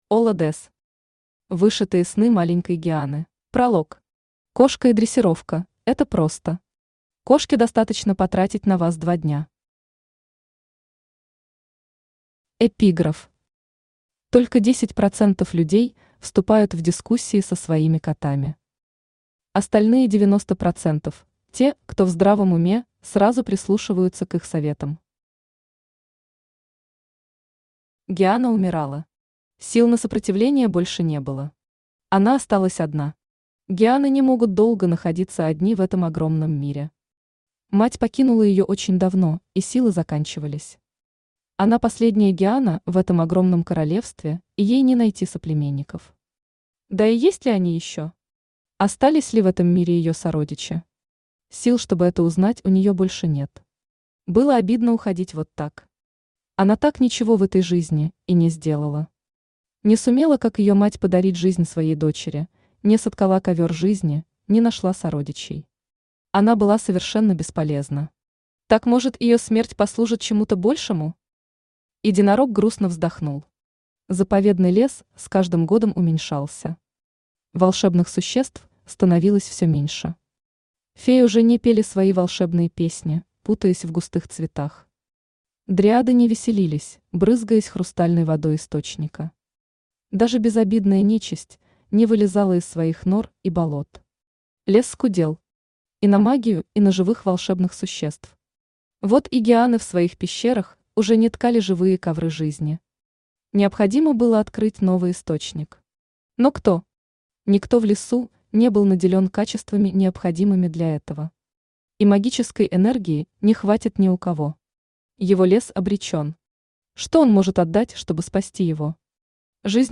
Аудиокнига Вышитые сны маленькой гианы | Библиотека аудиокниг
Aудиокнига Вышитые сны маленькой гианы Автор Олла Дез Читает аудиокнигу Авточтец ЛитРес.